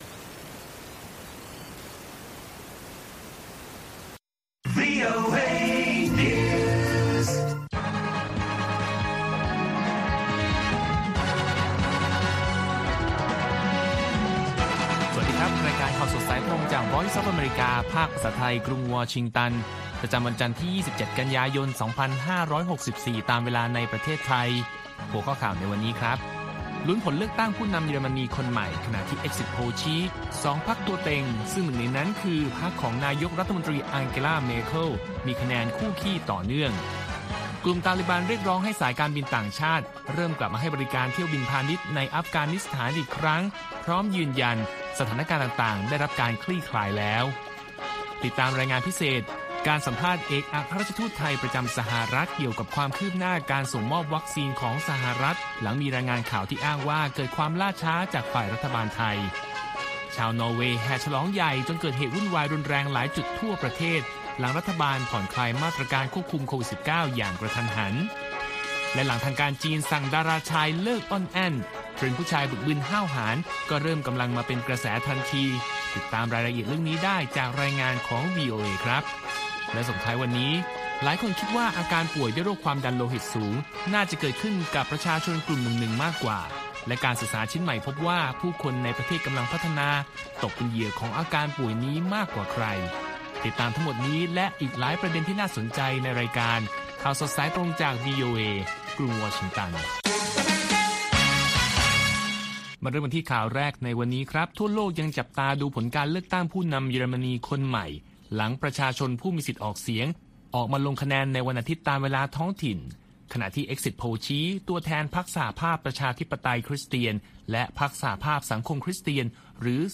ข่าวสดสายตรงจากวีโอเอ ภาคภาษาไทย 6:30 – 7:00 น. ประจำวันที่ 27 กันยายน 2564